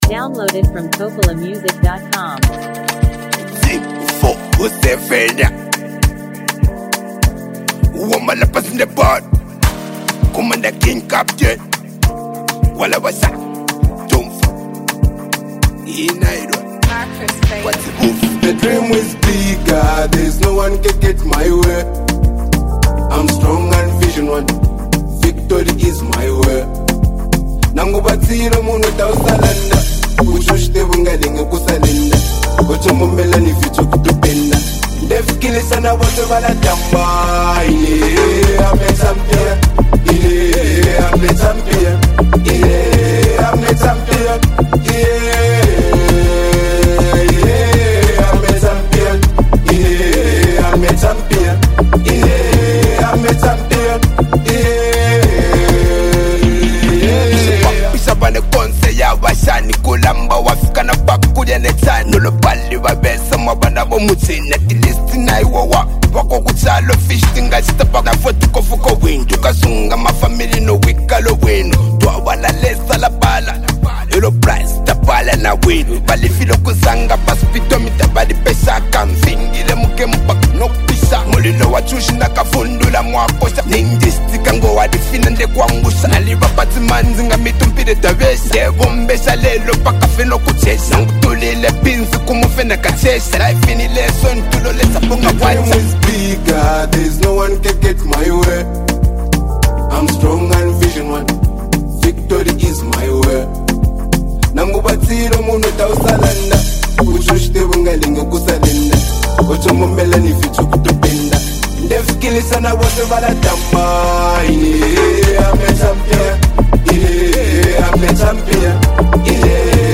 Sounds like you’re talking about a motivational anthem!